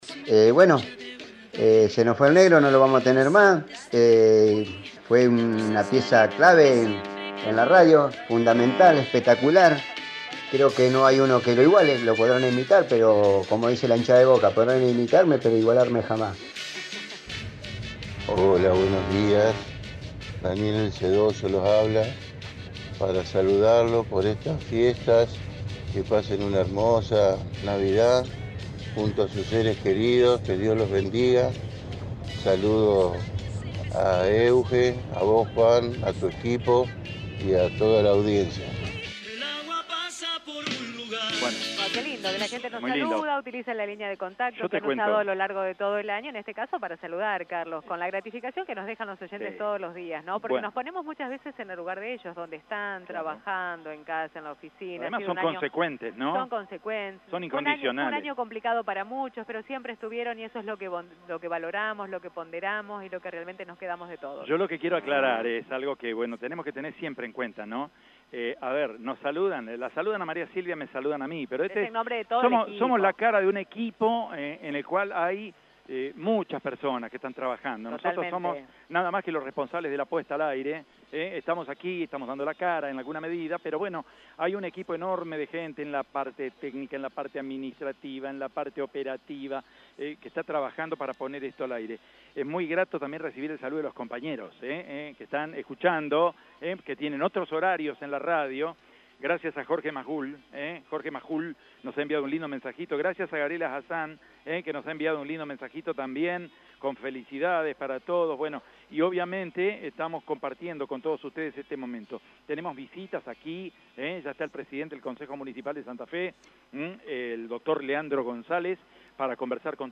El presidente del Concejo Municipal de Santa Fe, Leandro González, participó de la transmisión especial de Radio EME desde la Peatonal San Martín de la capital provincial.